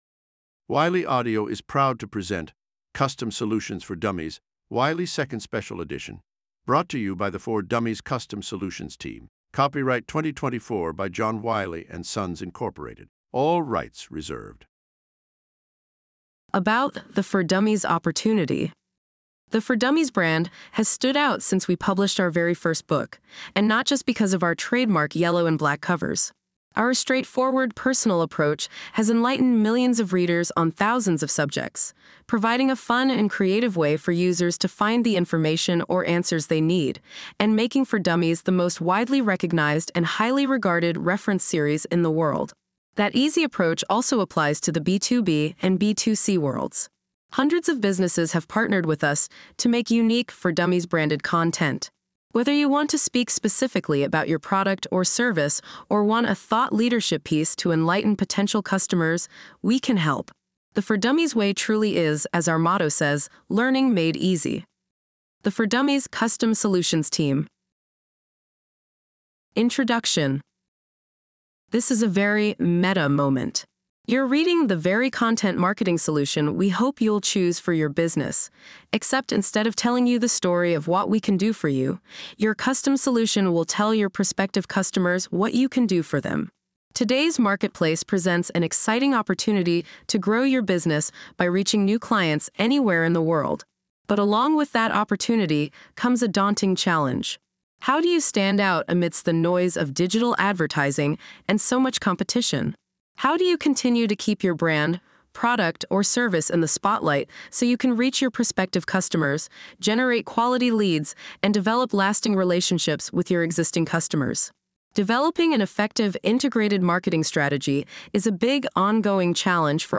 Audiobooks
Custom-Solutions-For-Dummies-2nd-Ed-Audiobook.mp3